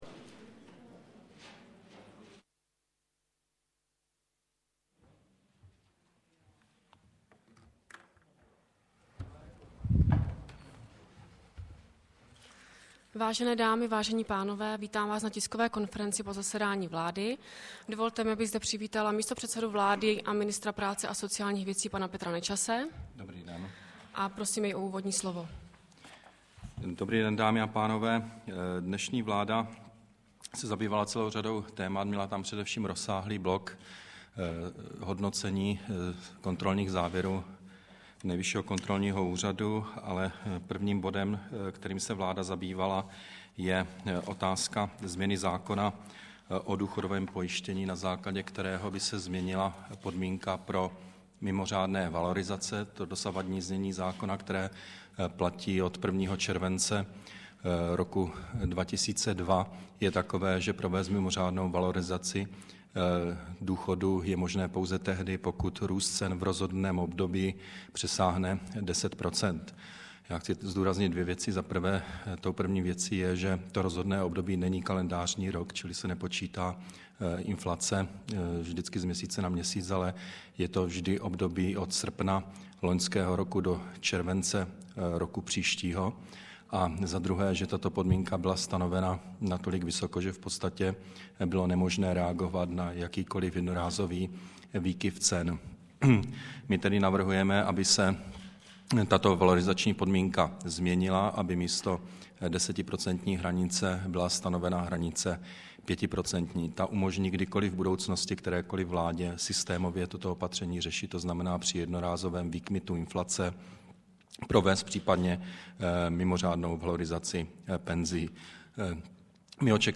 Tisková konference po jednání vlády ČR 10.3.2008